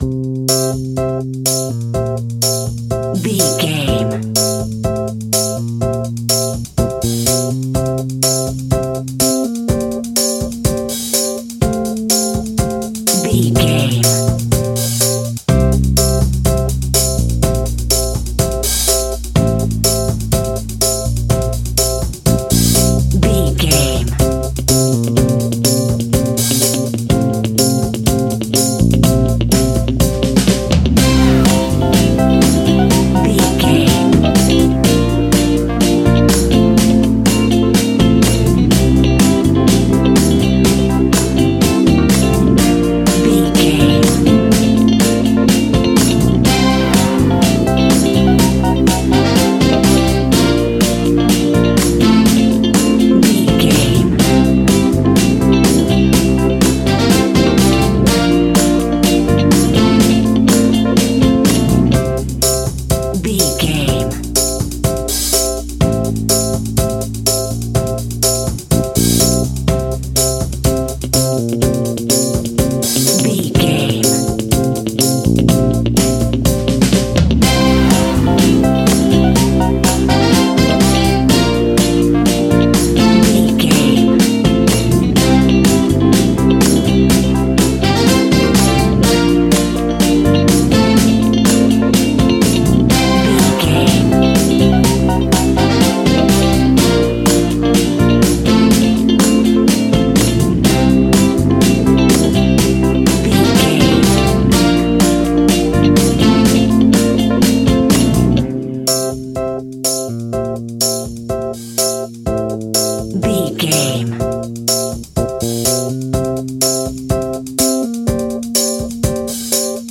Ionian/Major
cool
funky
uplifting
bass guitar
electric guitar
organ
percussion
drums
saxophone
groovy